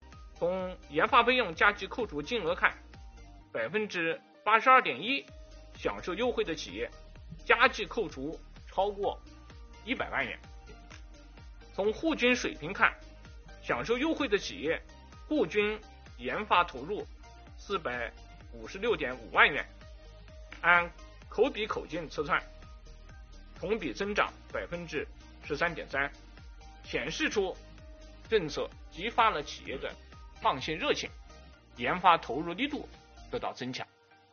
11月5日，国务院新闻办公室举行国务院政策例行吹风会，国家税务总局副局长王道树介绍制造业中小微企业缓税政策等有关情况，并答记者问。